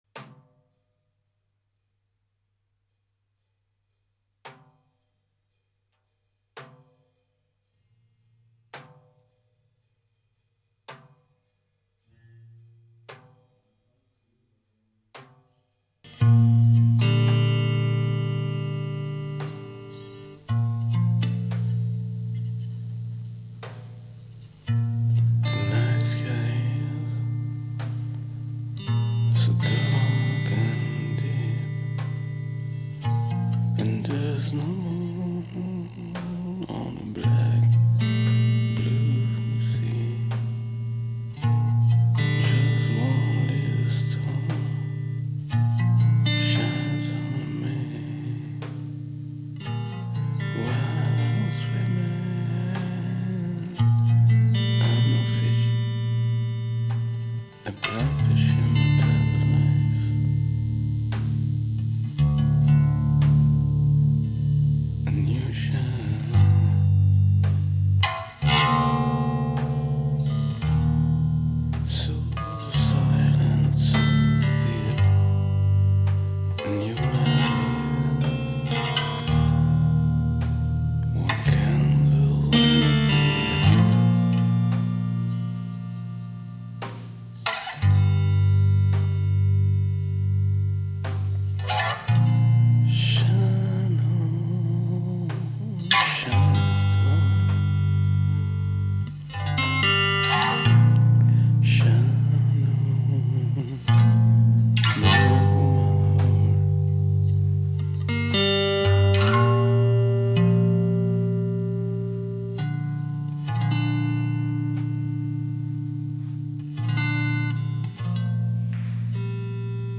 stereo
Live recorded
drums